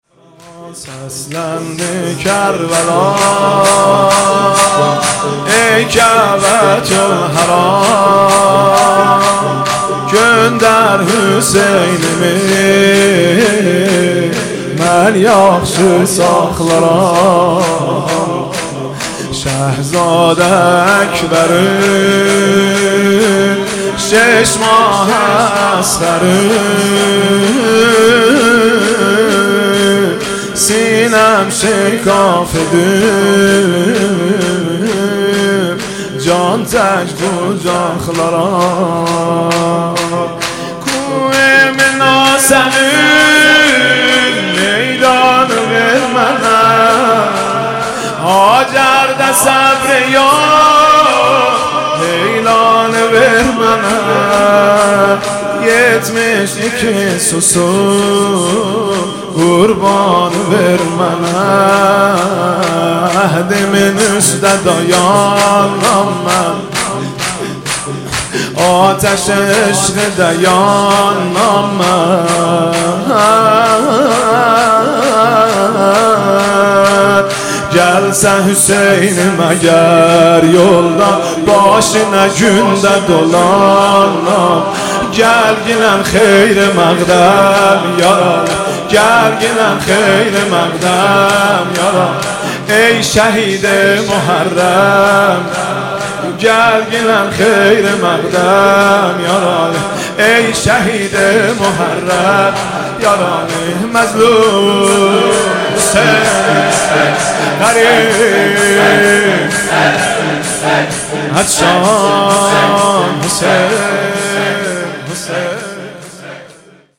محرم1402 شب دوم -شورترکی - سسلندی کربلا - مهدی رسولی
محرم1402 شب دوم
محرم1402 شب دوم شور ترکی مهدی رسولی